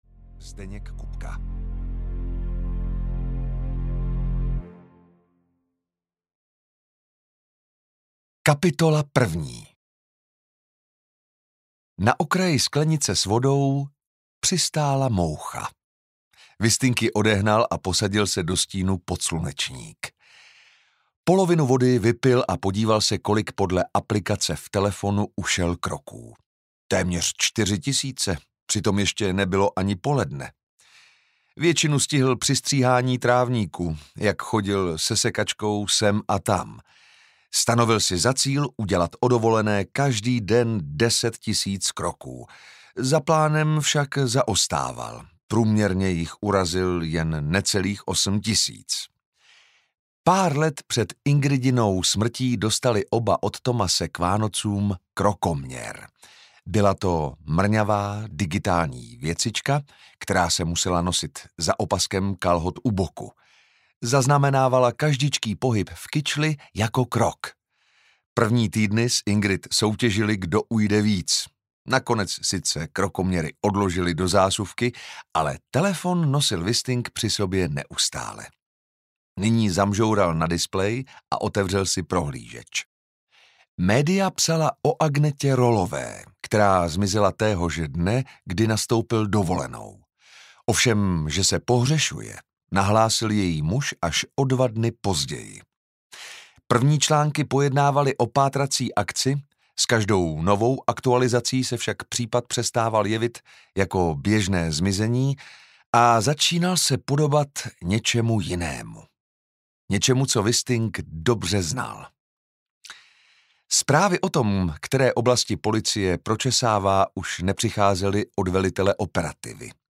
Otázka viny audiokniha
Ukázka z knihy